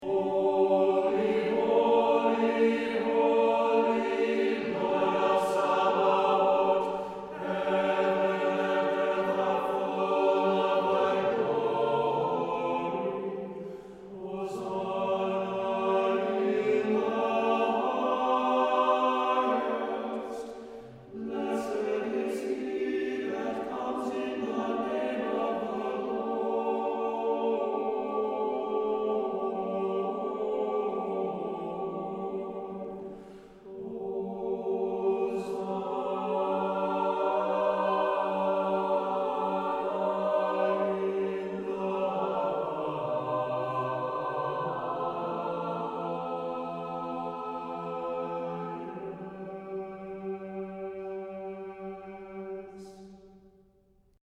Znamenny Chant